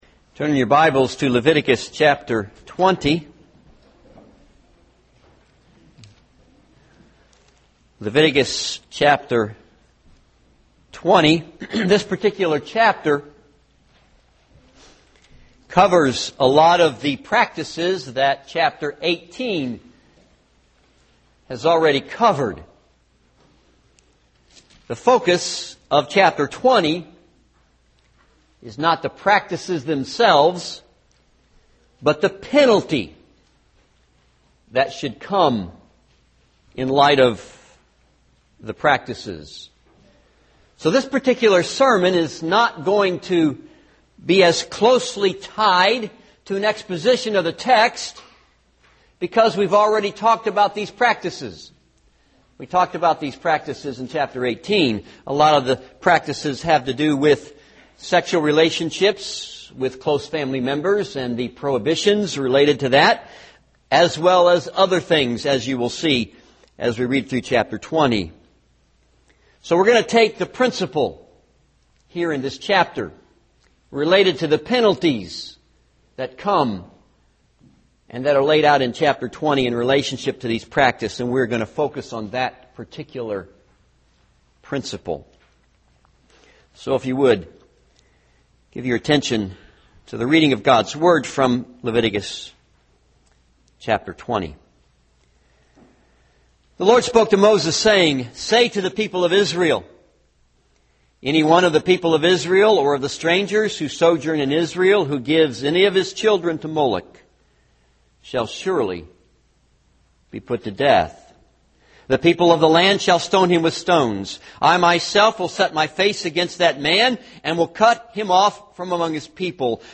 This is a sermon on Leviticus 20.